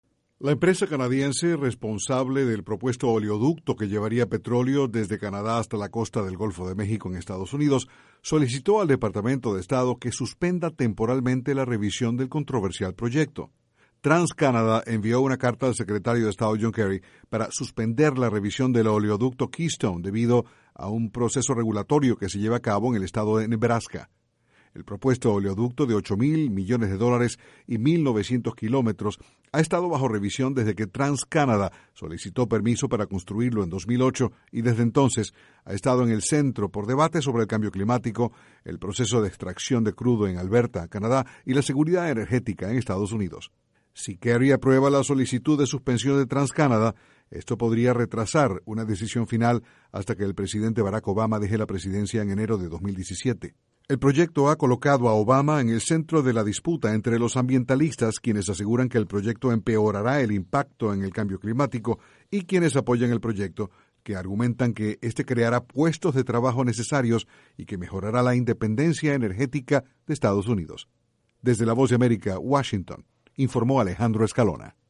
TransCanada solicitó a Estados Unidos que suspenda temporalmente la revisión del proyecto Keystone. Desde la Voz de América, Washington